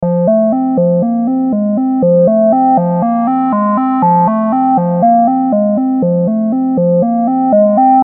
Chill Analog Synth Loop
Description: Chill analog synth loop. Create a dreamy vintage atmosphere with this chill analog synth loop.
Genres: Synth Loops
Tempo: 120 bpm
Chill-analog-synth-loop.mp3